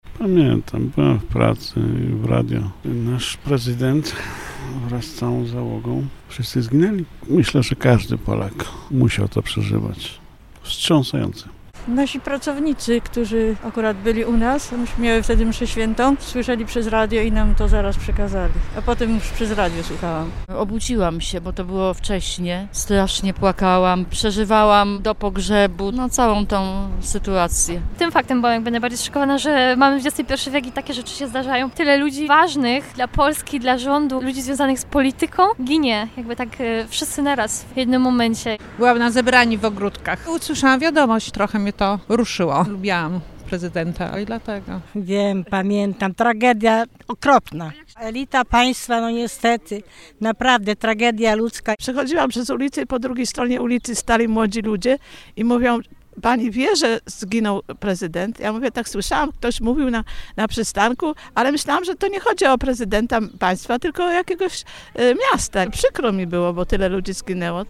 sonda_smolensk_dluga.mp3